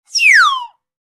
Short Fail Whistle Sound Effect
Description: Short fail whistle sound effect. This cartoon funny error whistle captures a short, comical mistake tone. Perfect for a failed attempt or ‘oops’ moment in games, videos, or apps.
Short-fail-whistle-sound-effect.mp3